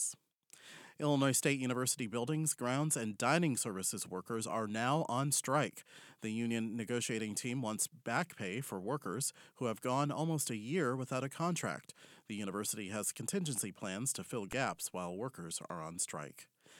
Striking faculty brought signs and chanted “When we fight, we win” during a noon rally Wednesday at the UIS colonnade.